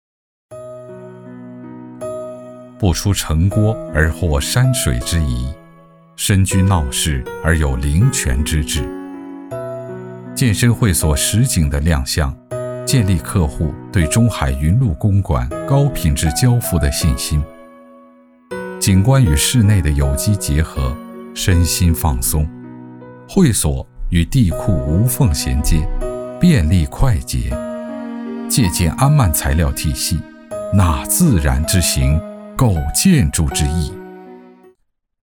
B男197号 | 声腾文化传媒
【广告】中海云麓公馆
【广告】中海云麓公馆.mp3